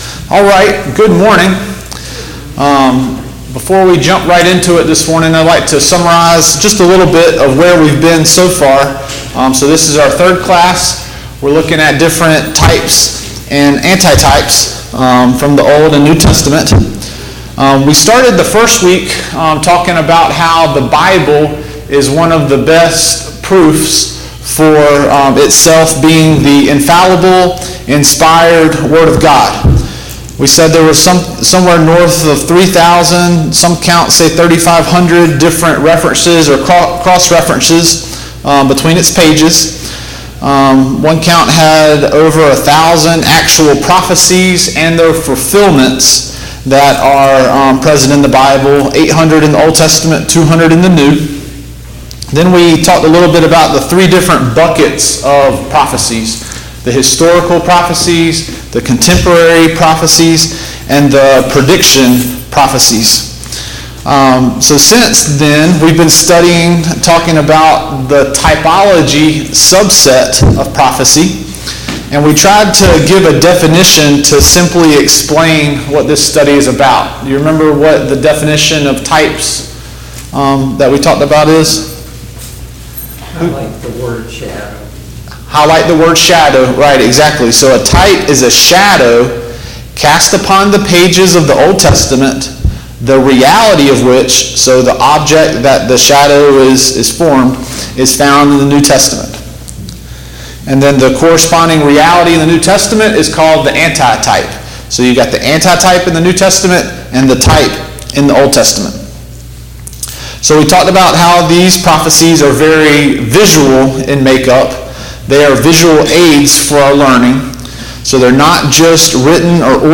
Study on Prophecy Service Type: Sunday Morning Bible Class « Paul’s Third Missionary Journey